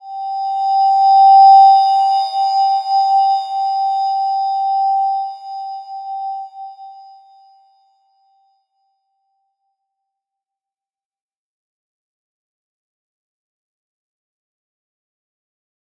Slow-Distant-Chime-G5-f.wav